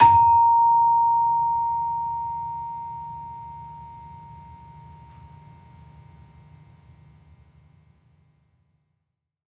Gender-3-A#4-f.wav